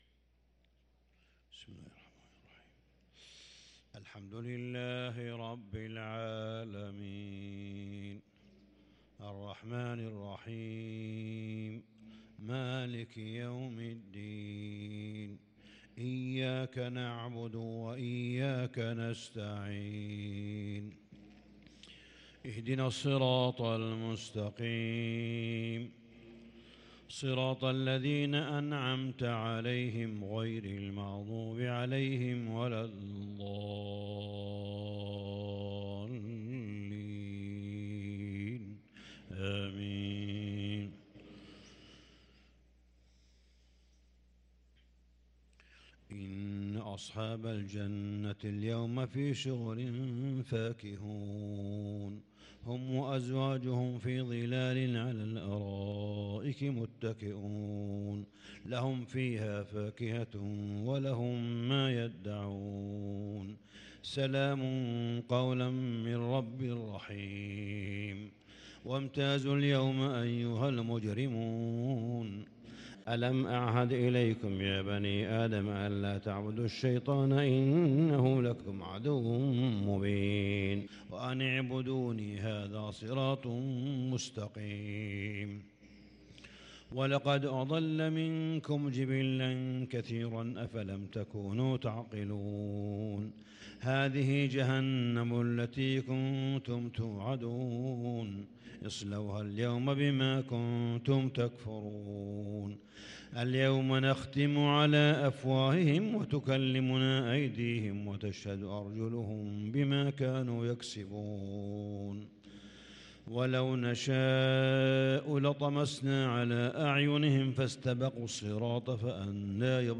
صلاة الفجر للقارئ صالح بن حميد 8 رمضان 1443 هـ